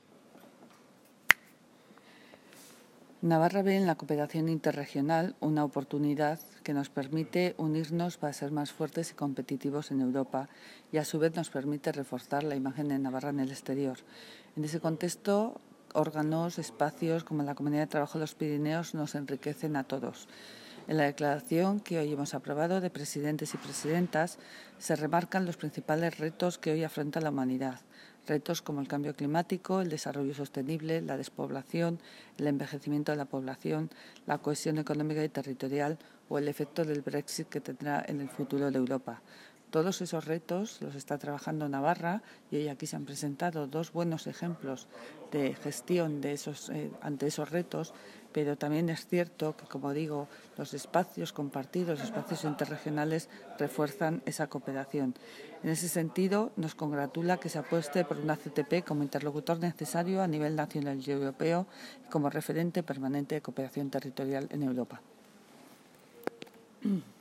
La consejera de Relaciones Ciudadanas Ana Ollo ha participado esta mañana en Jaca en el XXXVII Consejo Plenario de la Comunidad de Trabajo de los Pirineos
En su intervención Ana Ollo ha destacado, ante las delegaciones de las siete regiones transfronterizas participantes, la importancia y el enriquecimiento mutuo que supone el intercambio de ideas y conocimiento dentro de la cooperación transfronteriza. En este sentido, la consejera se ha reafirmado en la apuesta que hizo Navarra para que la labor de la CTP vaya más allá de la gestión de fondos europeos y pueda a sí desarrollar de manera efectiva acciones de la Estrategia Pirenaica.